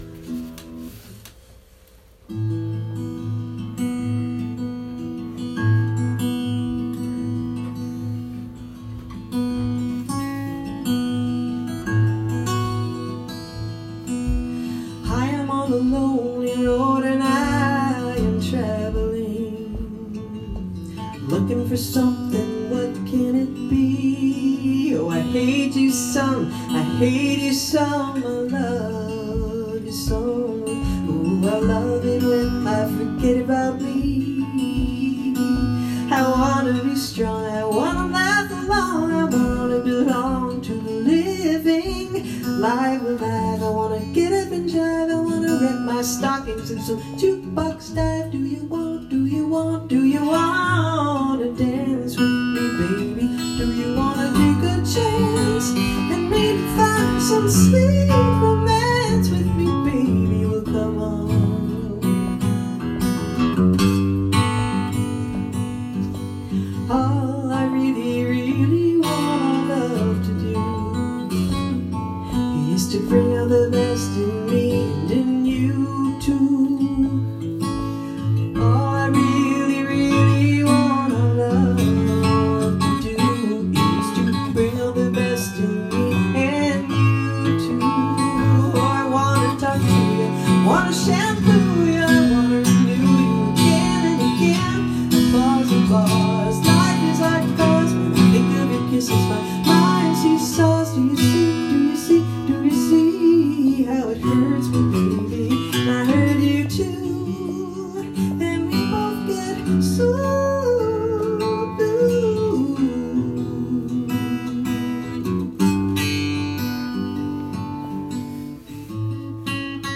vocal
guitar
bass